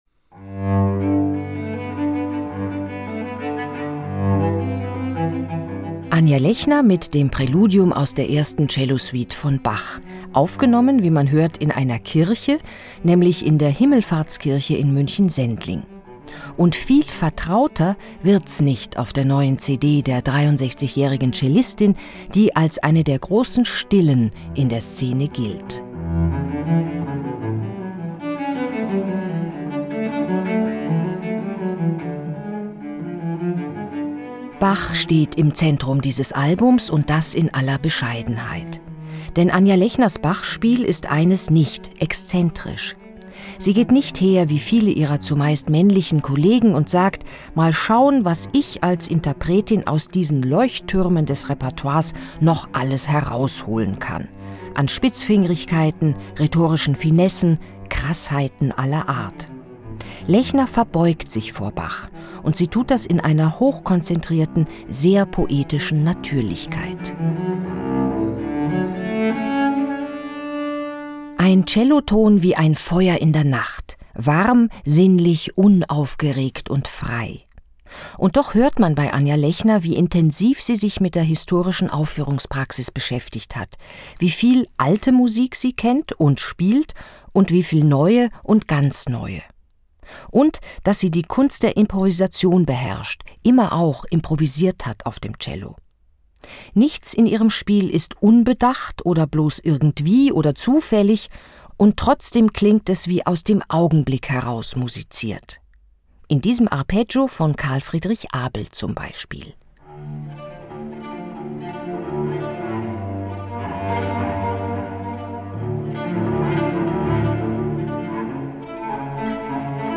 Radio-Podcasts in voller Länge kostenlos live hören: Anja Lechner spielt Cello-Werke von Bach, Abel und Hume: „Eine stille, großartige